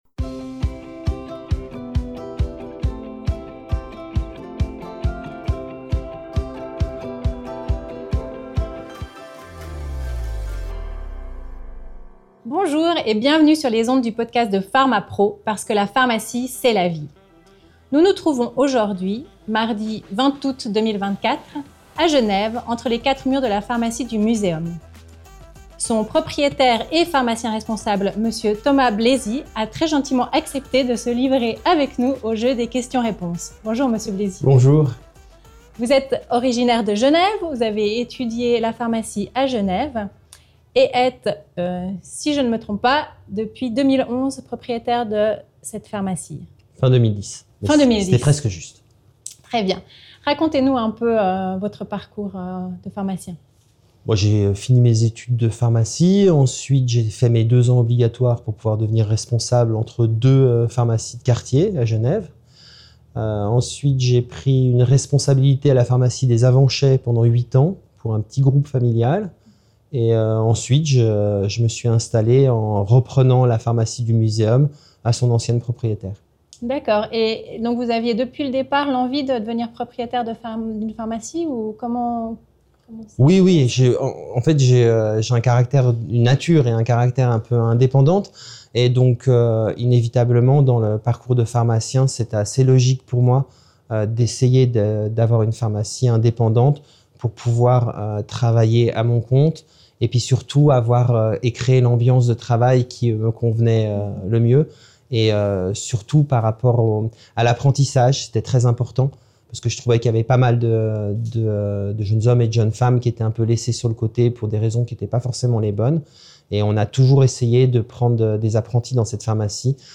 Interview avec le pharmacien et Conseiller national Thomas Bläsi